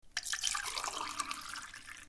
جلوه های صوتی
دانلود صدای ریختن آب در لیوان 3 از ساعد نیوز با لینک مستقیم و کیفیت بالا